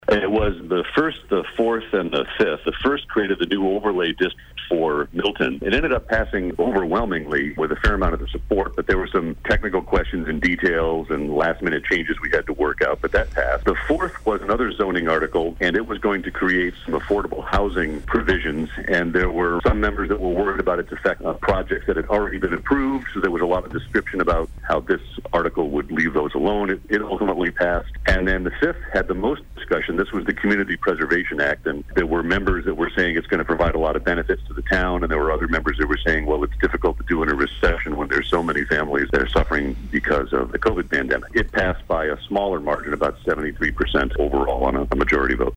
Town Moderator Robert Hiss tells WATD News there were three articles in particular that turned out to be the most contentious.